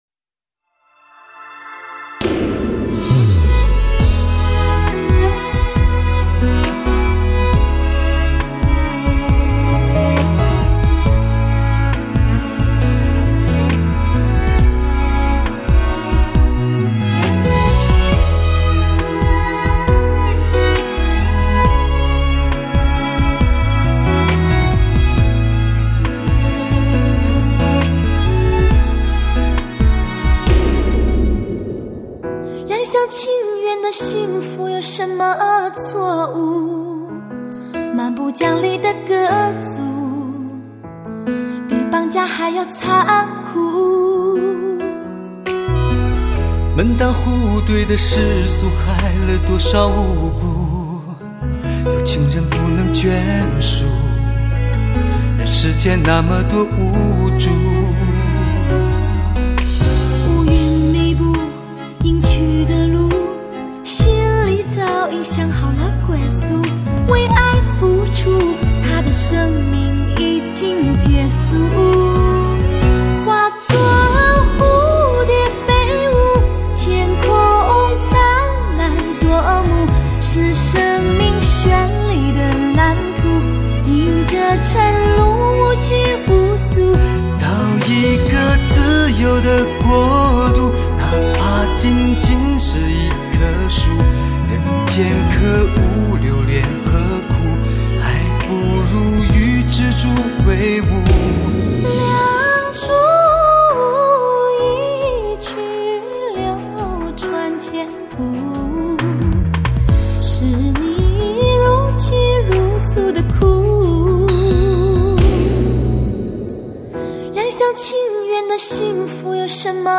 经典对唱震撼登场